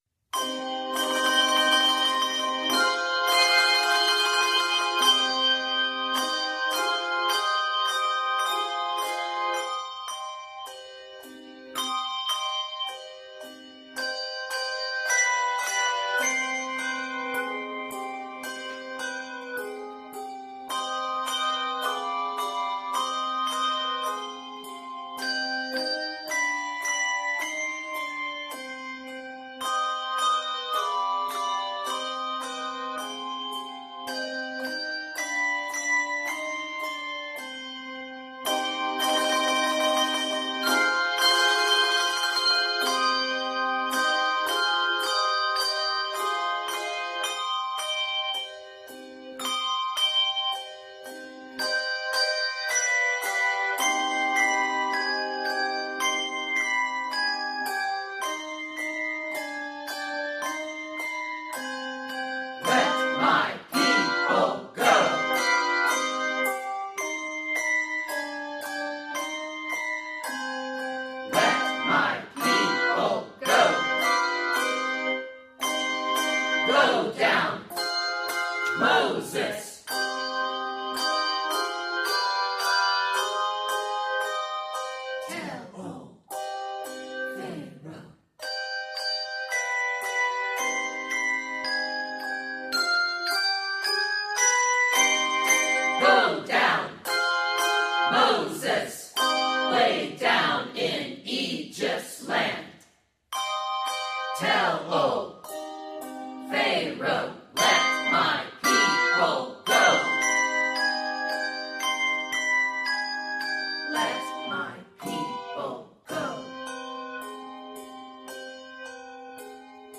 the combination of speech and music in dialog